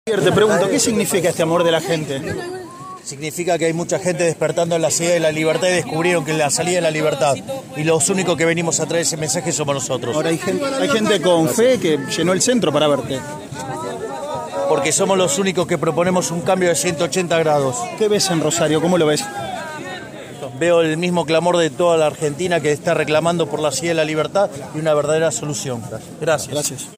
Audio. Javier Milei, de recorrida por Rosario, habló con el móvil de Cadena 3.
Si bien no hizo conferencia de prensa ni otorgó entrevistas, habló en exclusivo con el móvil de Cadena 3 Rosario.